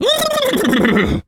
Animal_Impersonations
horse_neigh_01.wav